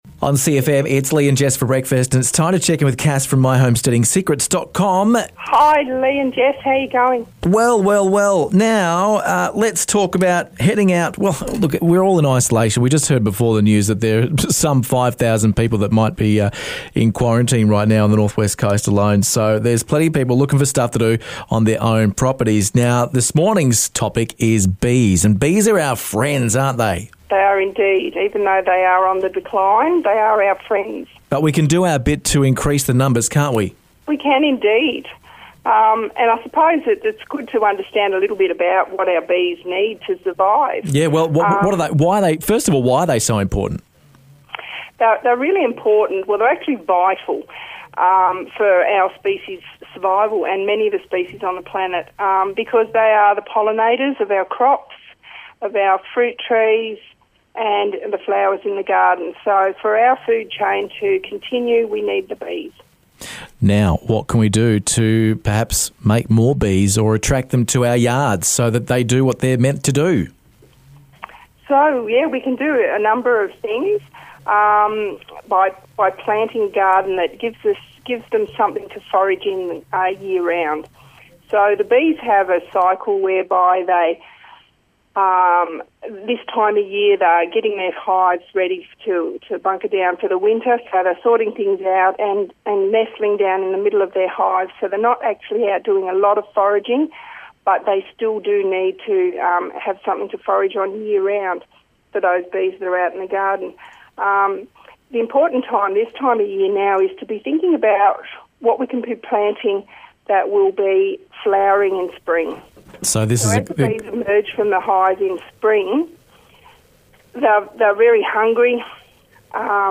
called in to let us know the importance of bees and how we can attract them to our gardens.